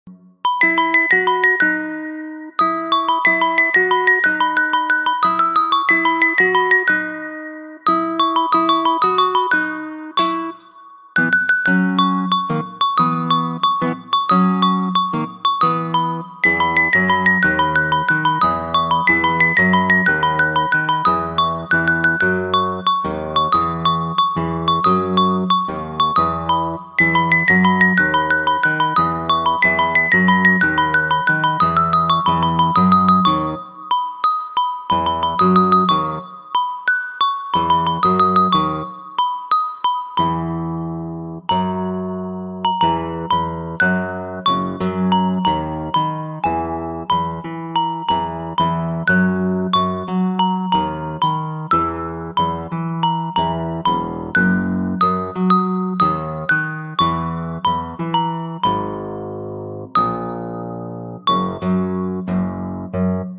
Genre: Nada dering alarm